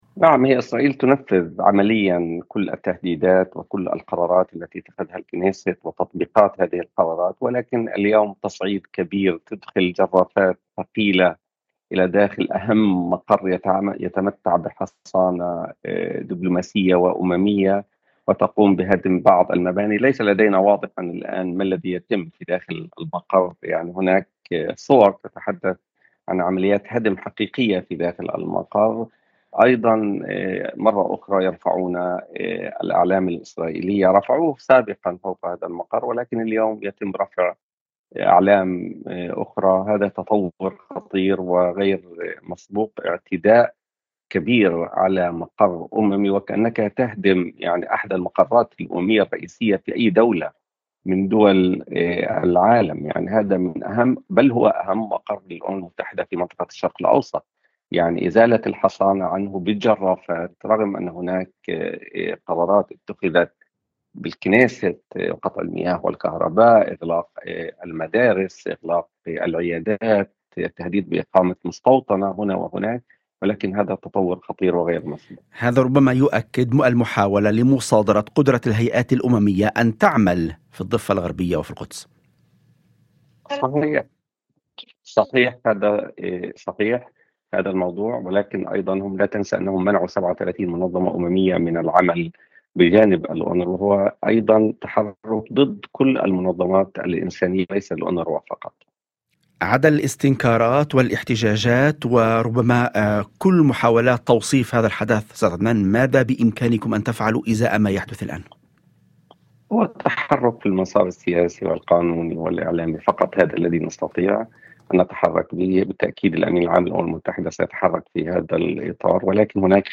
وأضاف في مداخلة هاتفية لبرنامج "يوم جديد"، على إذاعة الشمس، أن جرافات ثقيلة دخلت إلى أحد أهم مقار الأونروا في المنطقة، وهو مقر يتمتع بحصانة دبلوماسية وأممية، وشرعت بهدم أجزاء منه، لافتا إلى أن الصورة الميدانية لا تزال غير مكتملة حتى الآن، لكن المؤشرات تؤكد تنفيذ عمليات هدم فعلية داخل المقر.